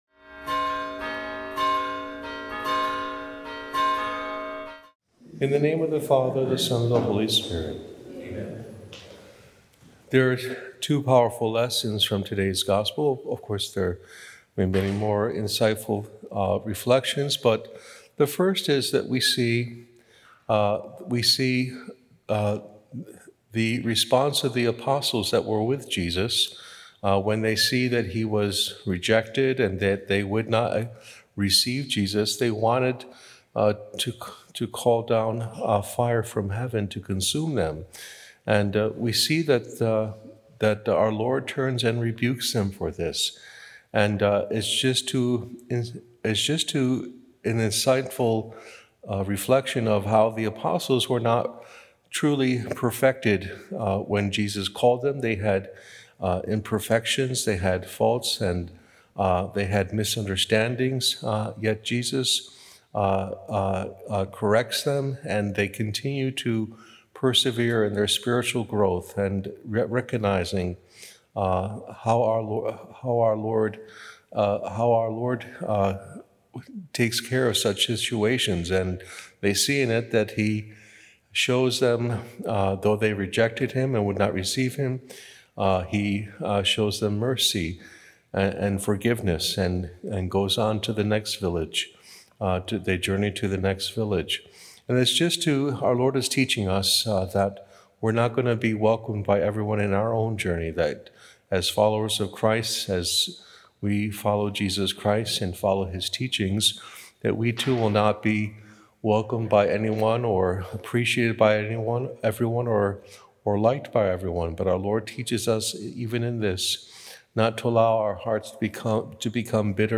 Jesus on Mercy & St Jerome- Sep 30 – Homily